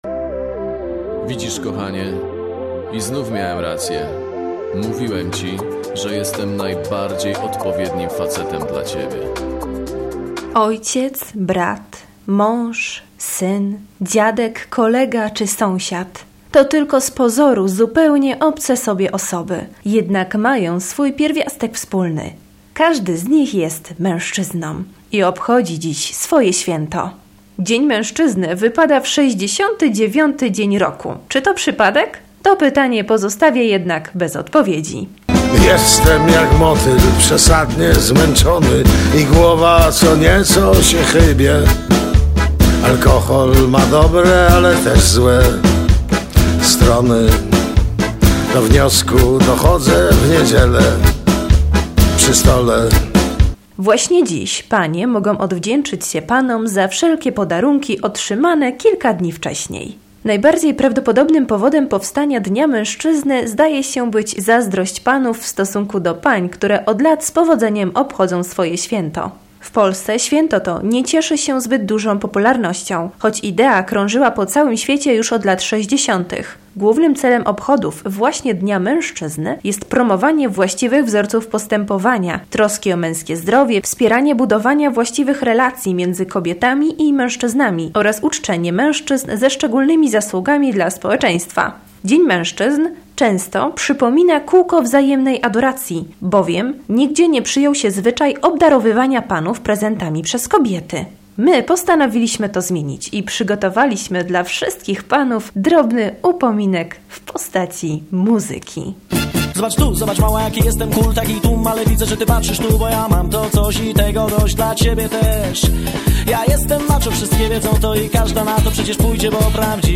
Felieton do wysłuchania 10 marca o godz. 13.15, 16.15 oraz 19.15.